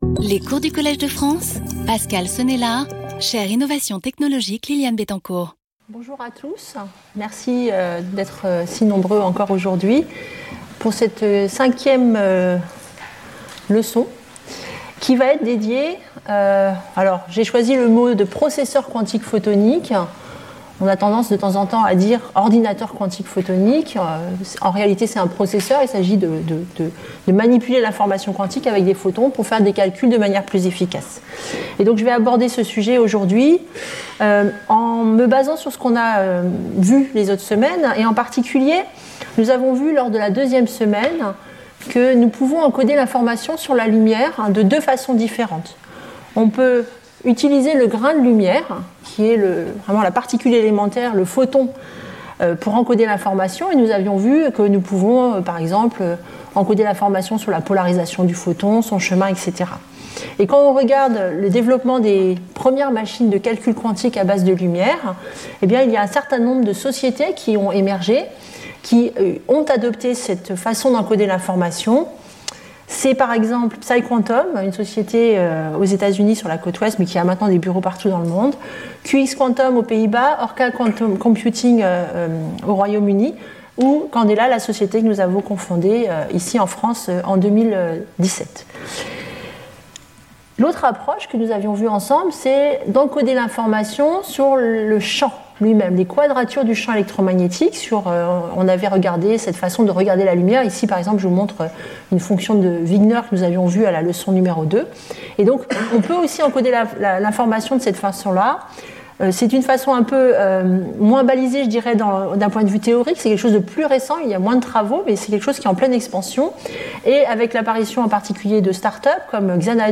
Ce cours décrira le fonctionnement des ordinateurs quantiques photoniques et les architectures envisagées. Ces processeurs exploitent tous l’interférence quantique de photons identiques, qui a représenté un défi scientifique et technologique important ces dernières décennies.